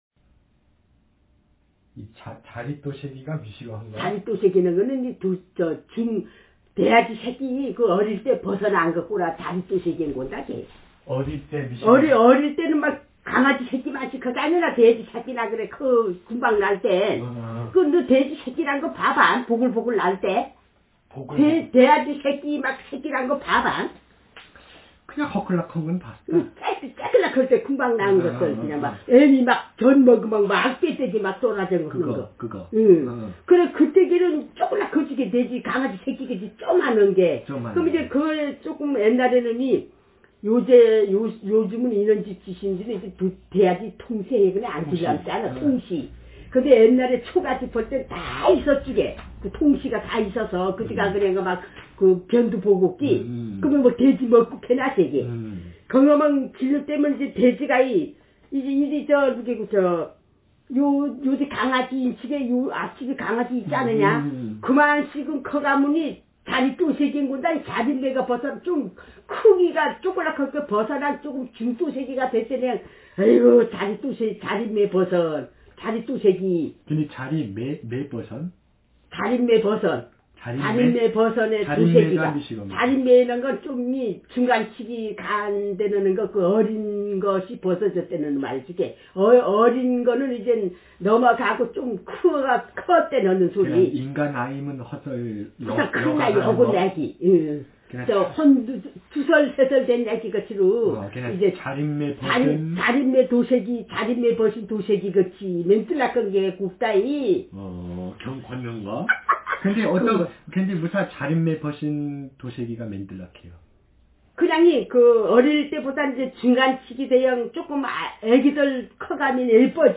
Speaker sexf
Text genreconversation